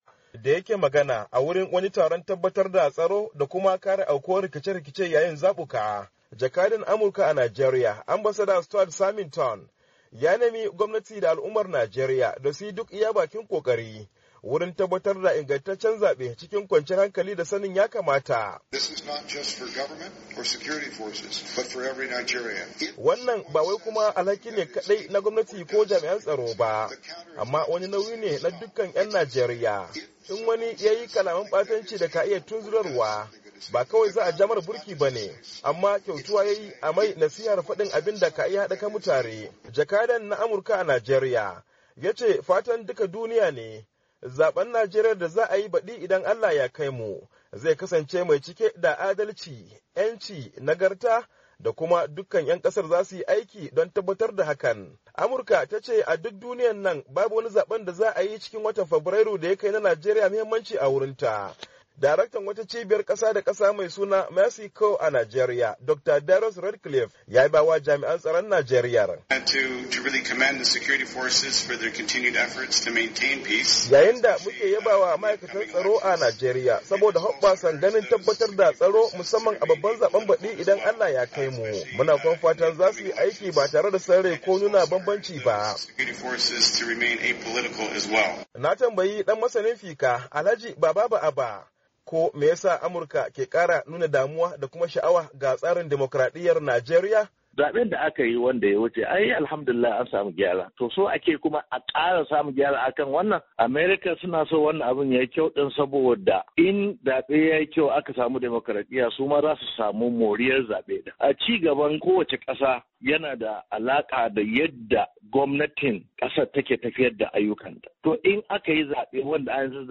Da yake jawabi a wani taron tabbatar da an gudanar da Zaben cikin kwanciyar hankali, tare da kaucewa rashin hankali, Jakadan Amurka a Najeriya, Ambasada Stuart Symington ya ce duk duniya babu inda za ai Zabe mai mahimmancin da ya kai na Najeriya.